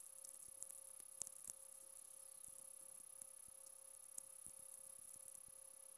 Forest, Virginia